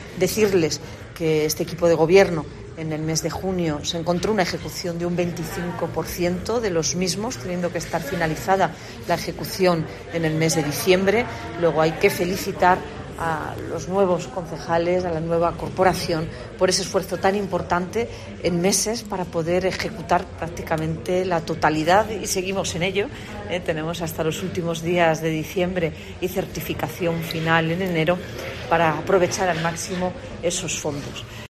Marifrán Carazo, alcaldesa de Granada